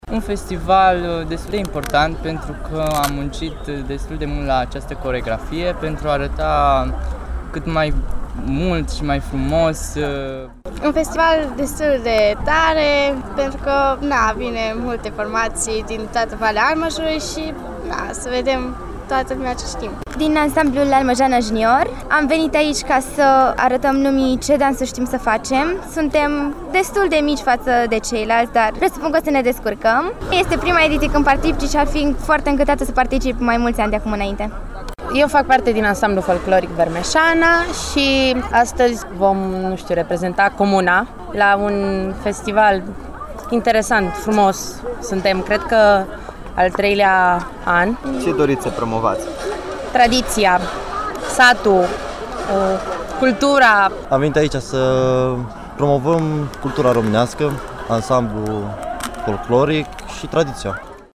Am stat de vorbă cu câţiva dintre membri ansamblurilor:
Dansatori.mp3